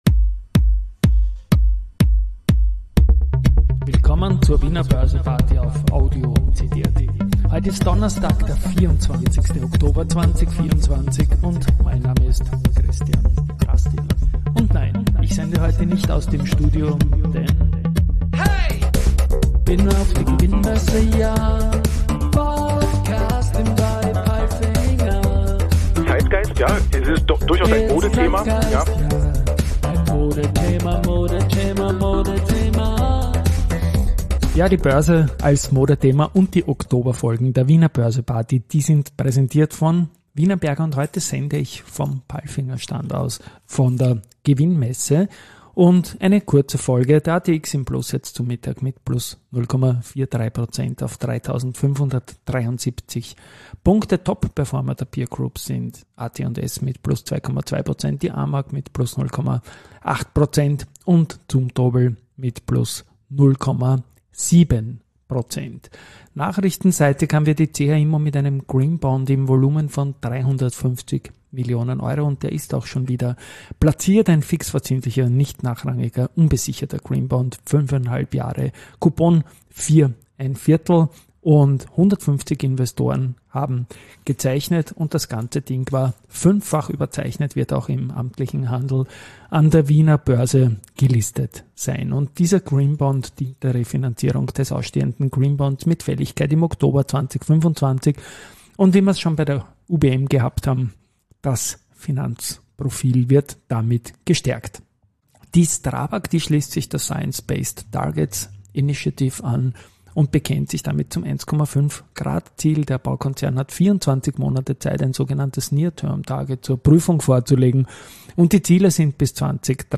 Wiener Börse Party #766: Kurze Folge live von der Gewinn-Messe mit Palfinger-Intro, weiters AT&S, CA Immo, Strabag, Pierer Mobility